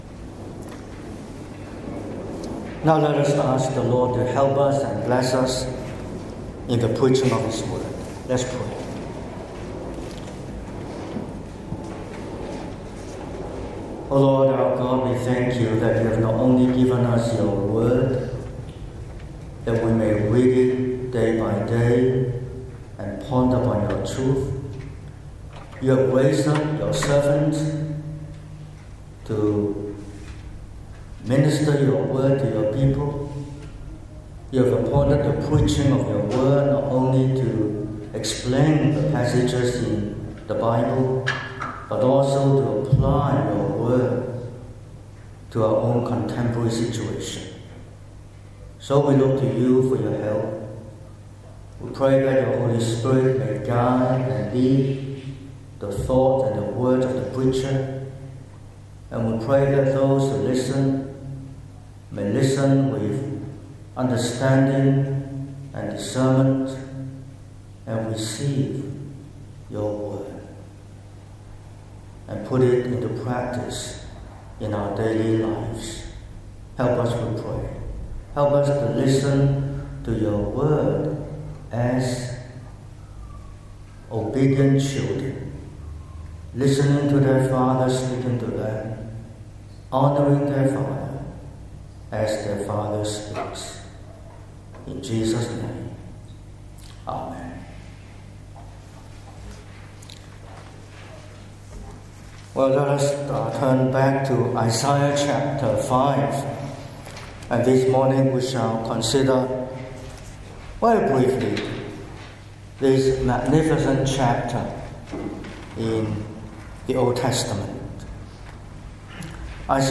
12/10/2025 – Morning Service: A Vineyard Song – Isaiah ch. 5